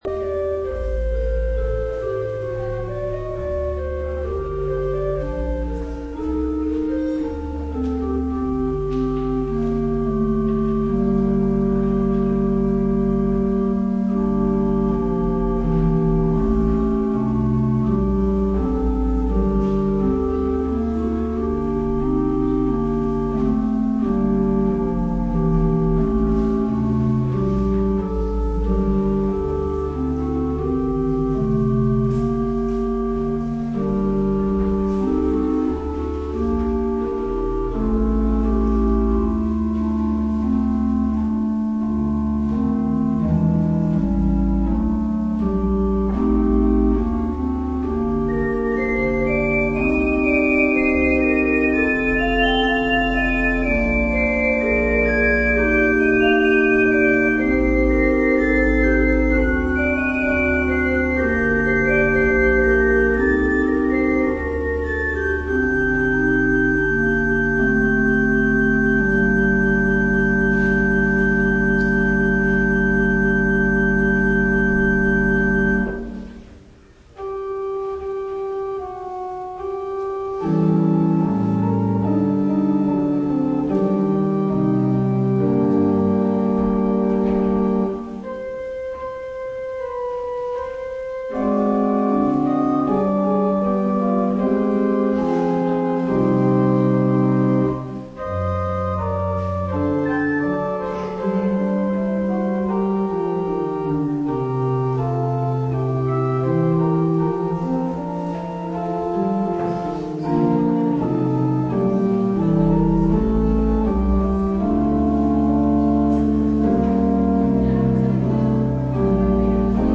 14 februari 2016 ochtend: Lukas 9:23 - Pauluskerk Gouda